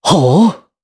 Esker-Vox_Happy4_jp.wav